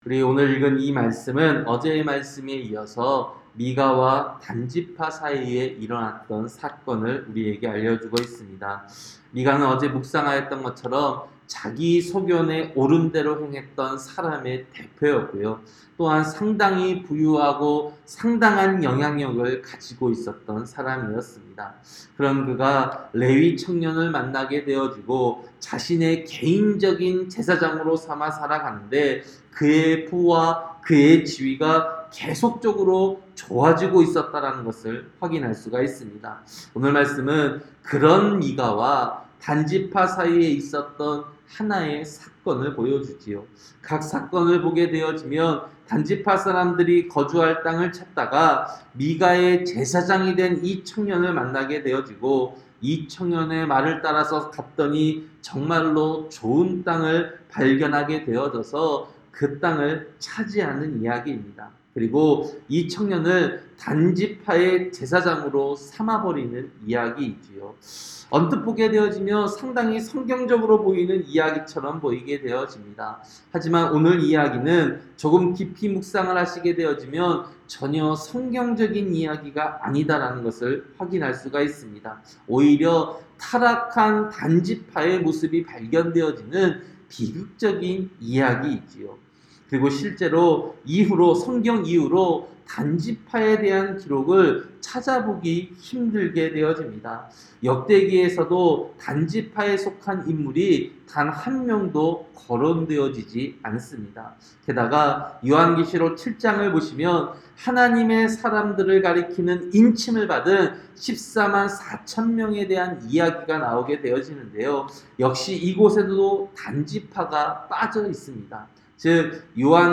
새벽설교-사사기 18장